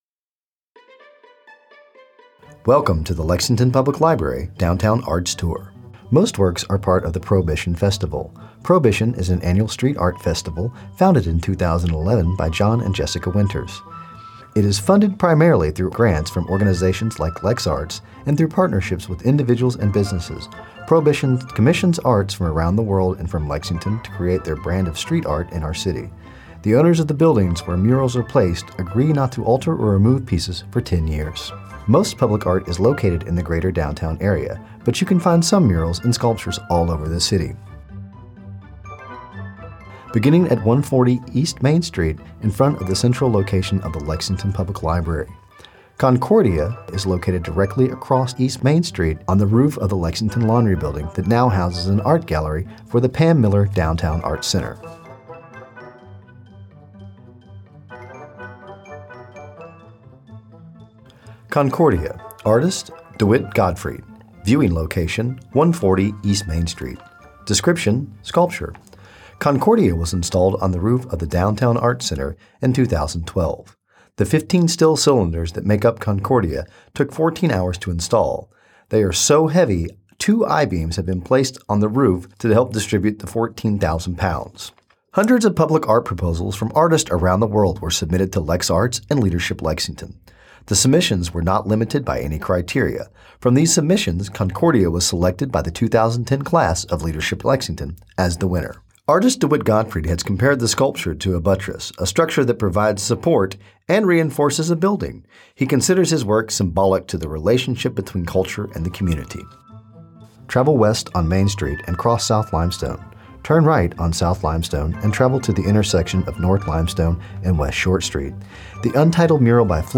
For the single MP3, music will play between the stops.
downtown_public_art_tour.mp3